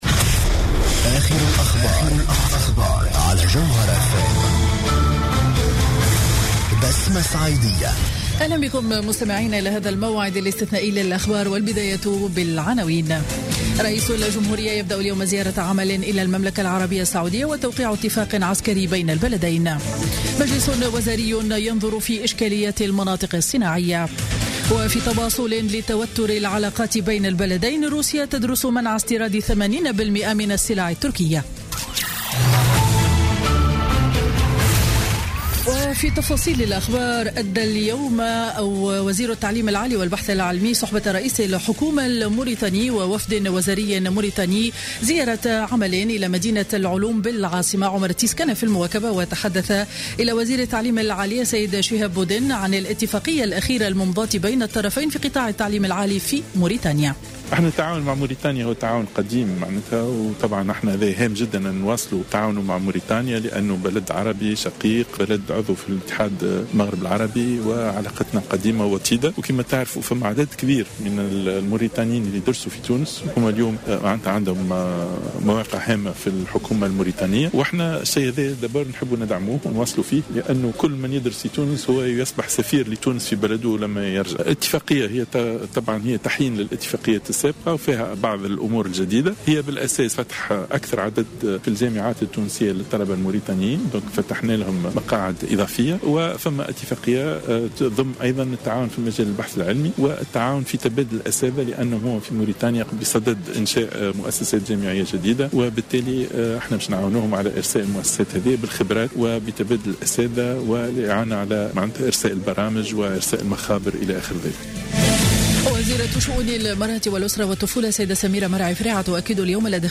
نشرة أخبار السابعة مساء ليوم الثلاثاء 22 ديسمبر 2015